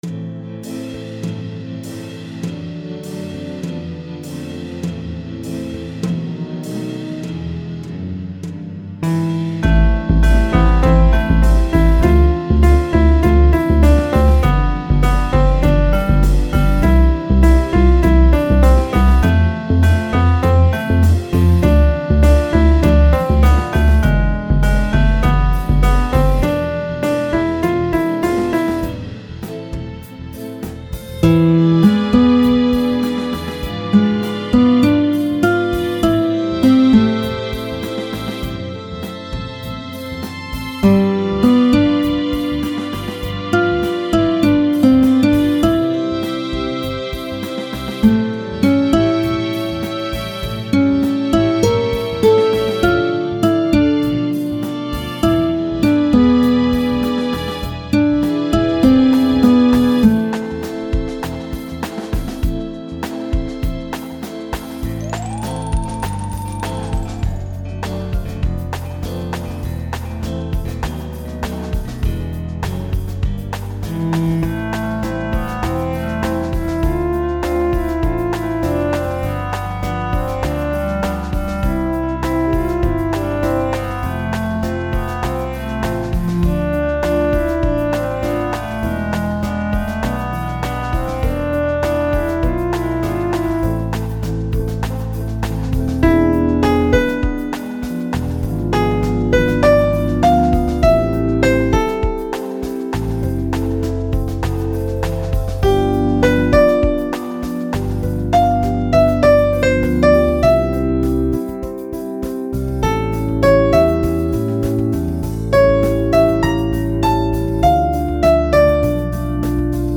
Мелодія для пісні № 66